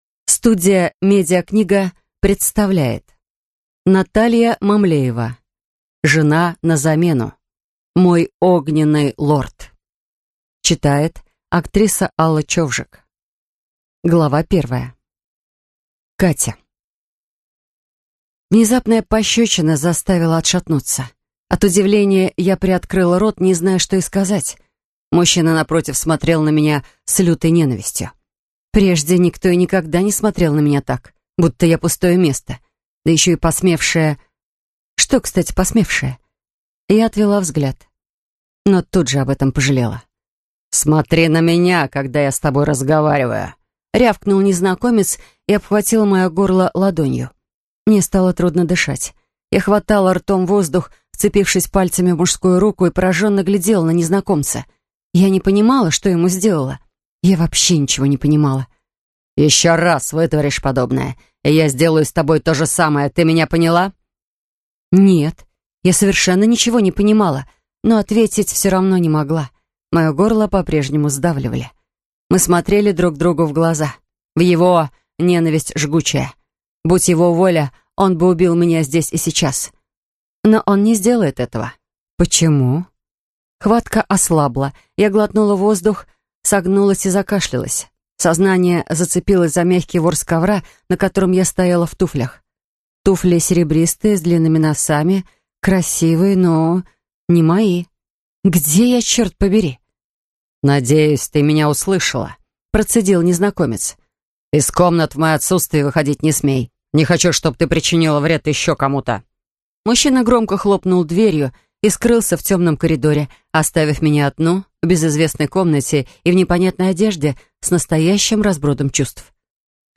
Аудиокнига Жена на замену: Мой огненный лорд | Библиотека аудиокниг